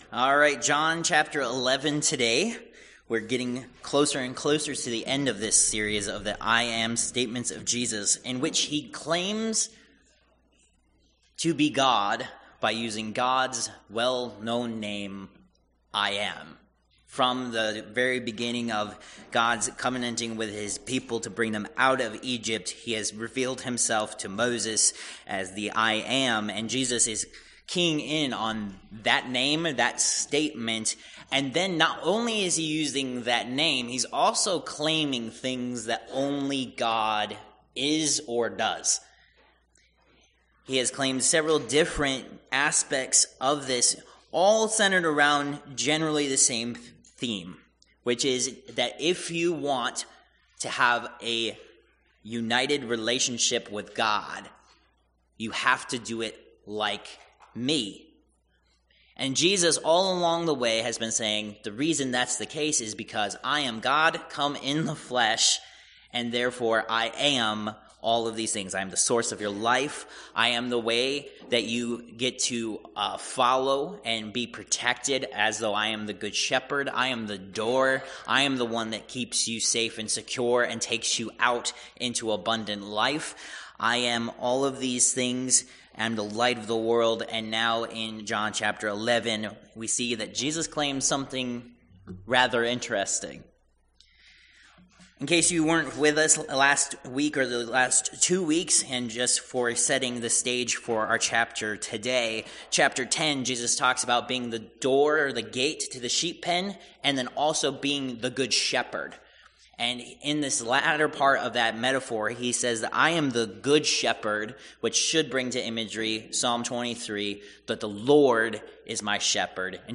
I AM Passage: John 11:1-54 Service Type: Worship Service « Protected